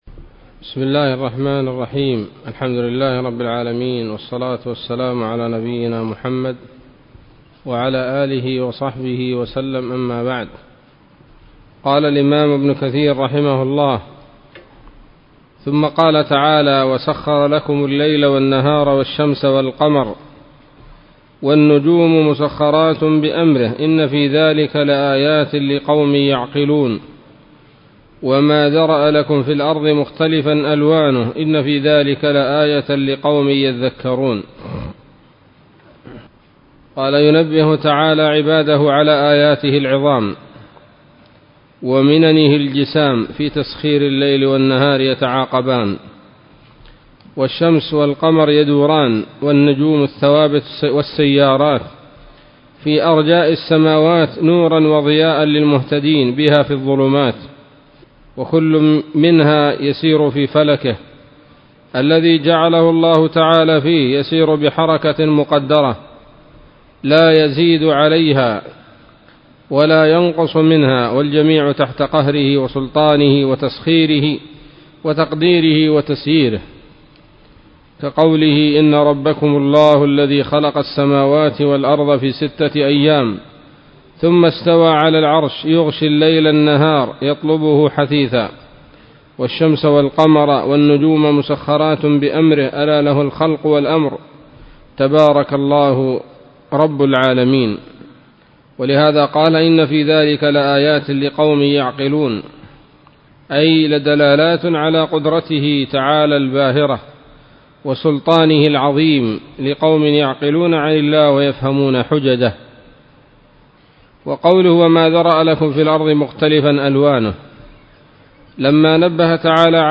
الدرس الرابع من سورة النحل من تفسير ابن كثير رحمه الله تعالى